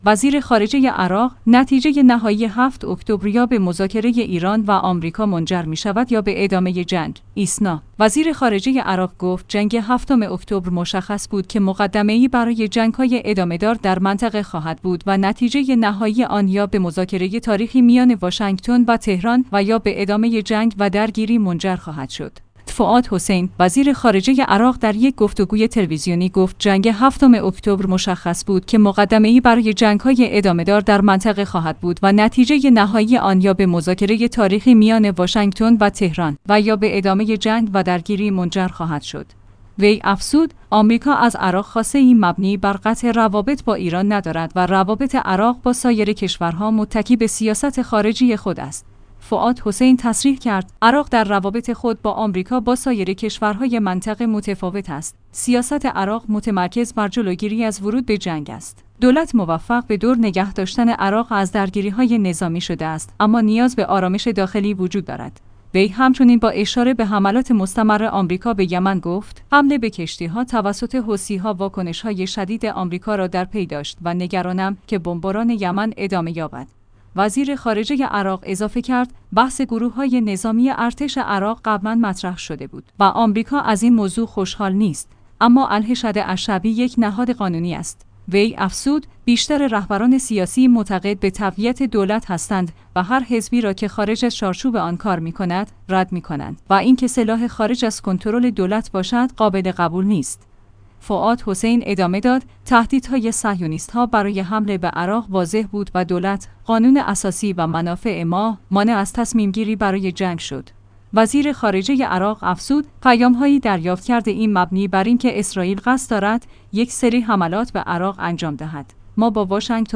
«فواد حسین» وزیر خارجه عراق در یک گفت‌وگوی تلویزیونی گفت جنگ هفتم اکتبر مشخص بود که مقدمه ‌ای